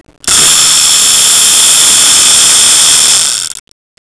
Köstebek kovucu her 50 saniyede bir çıkardığı ses ile köstebekleri rahatsiz ederek bulunduklari ortamdan kovar. 24 Ay garantilidir.
Cihazın sesini dinlemek için buraya tıklayın Ultrasonik ve sonik Köstebek kovucu Click here for English
vibrasonic.wav